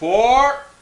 Fore Sound Effect
fore-1.mp3